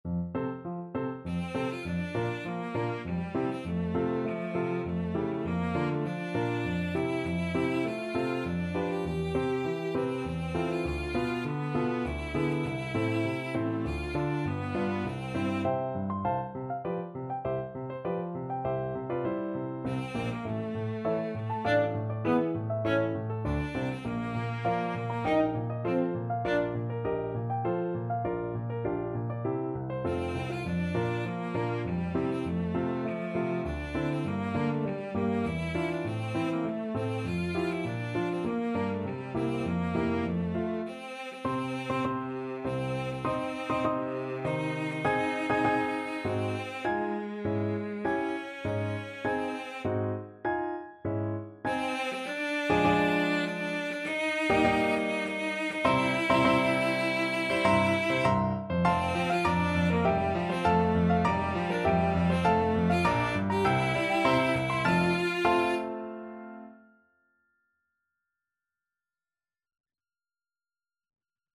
Cello
F major (Sounding Pitch) (View more F major Music for Cello )
3/4 (View more 3/4 Music)
~ = 100 Tempo di Menuetto
Classical (View more Classical Cello Music)